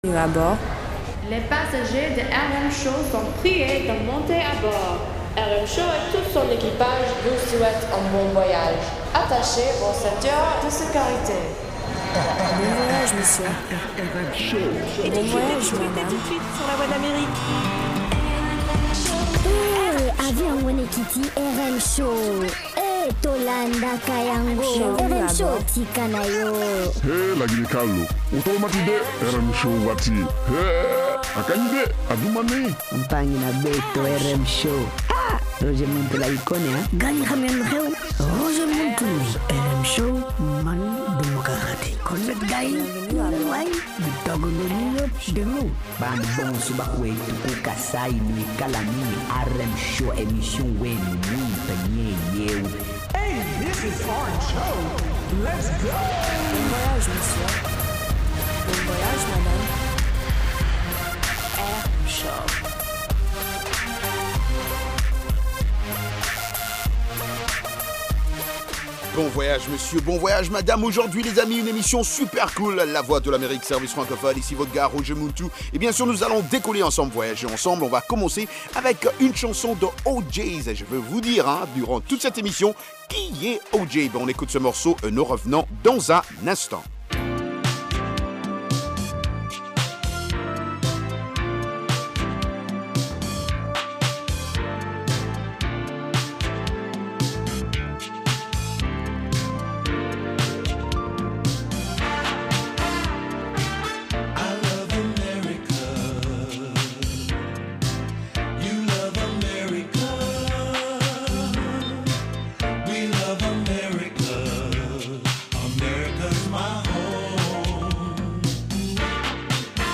R&B et Rock